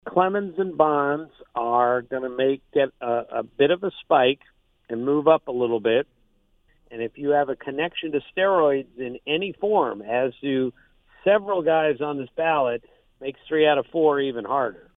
Kurkjian gave his expectations for tonight’s Hall of Fame announcement.